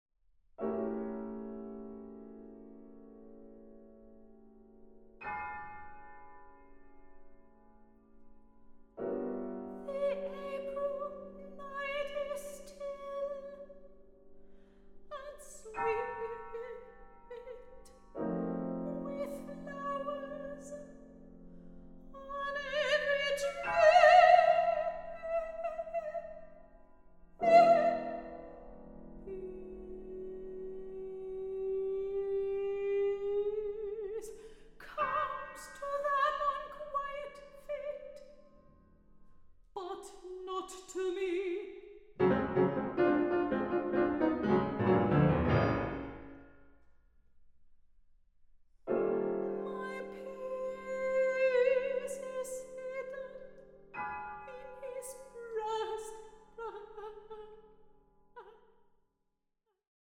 soprano
clarinet
piano